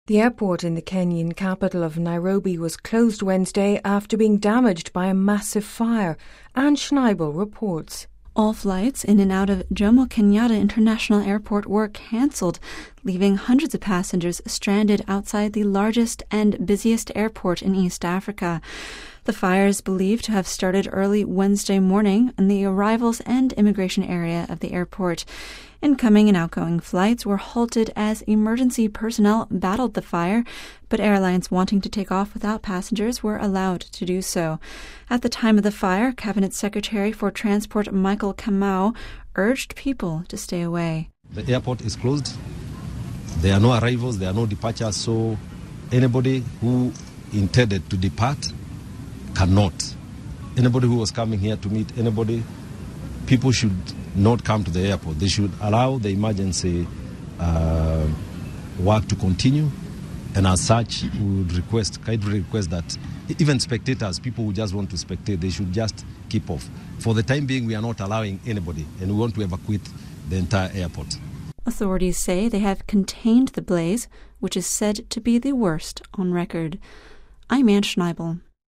(Vatican Radio) The airport in the Kenyan capital of Nairobi was shut down after being damaged by a massive fire. All flights in and out of Jomo Kenyatta International Airport were cancelled leaving hundreds of passengers stranded outside the largest and busiest airport in east Africa.